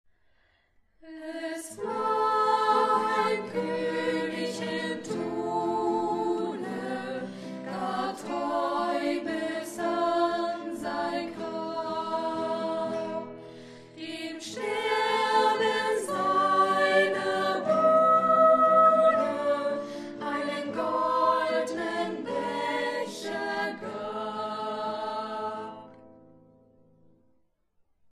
Dieses Lied wurde vom Sextett der Freien Waldorfschule Halle eingesungen.